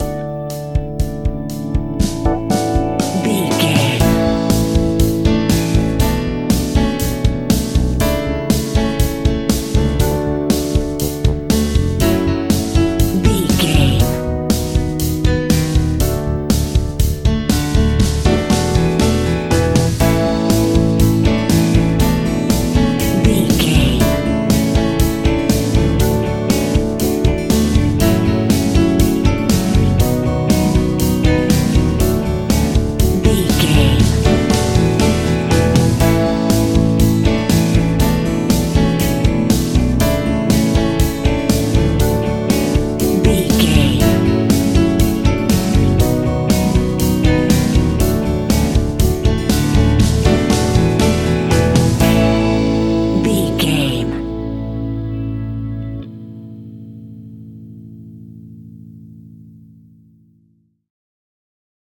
Ionian/Major
pop rock
indie pop
energetic
uplifting
instrumentals
upbeat
groovy
guitars
bass
drums
piano
organ